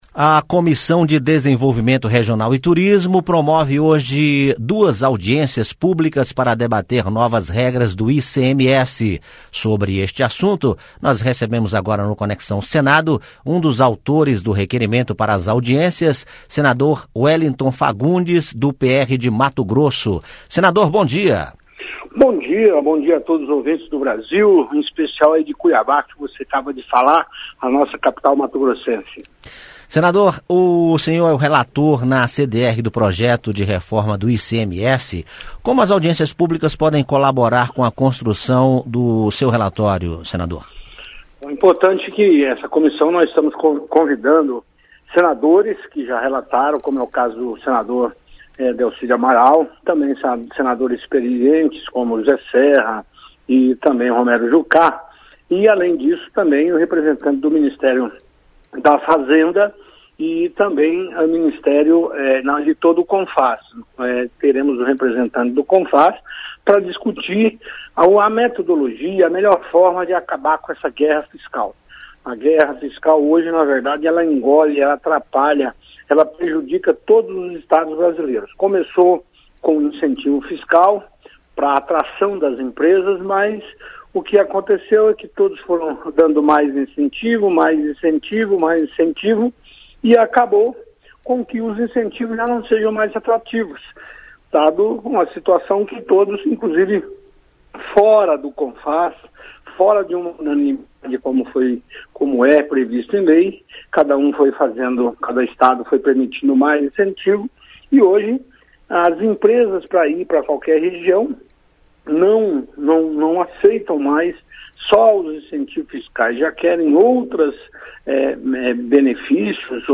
O senador Wellington Fagundes ( PR/MT ), relator do projeto PRS 01/2013 , que estabelece alíquotas do Imposto sobre Operações Relativas à Circulação de Mercadorias e sobre Prestação de Serviços de Transporte Interestadual e Intermunicipal e de Comunicação - ICMS, nas operações e prestações interestaduais, diz em entrevista ao programa Conexão Senado , da Rádio Senado, que a intenção do projeto é acabar com a guerra fiscal